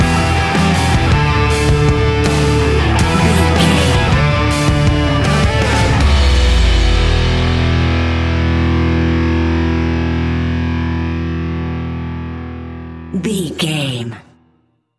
Ionian/Major
E♭
hard rock
heavy rock
distortion